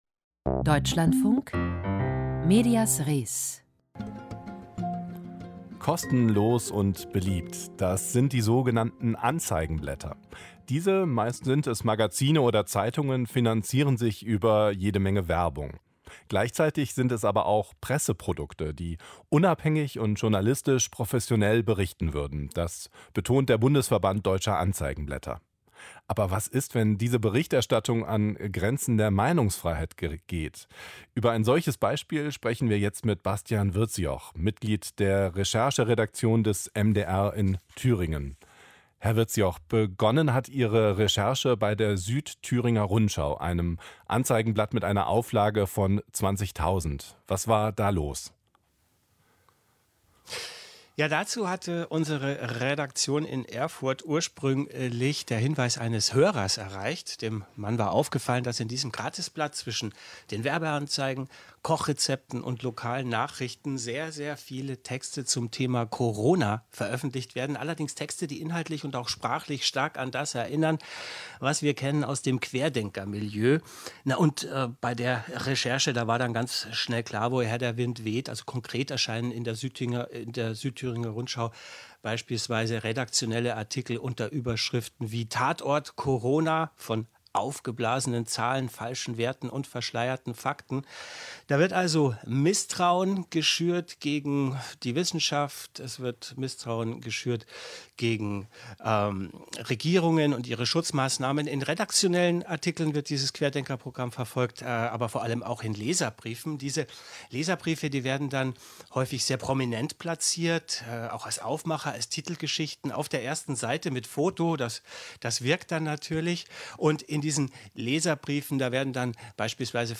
Kostenlose Anzeigenblätter schüren Misstrauen - Interview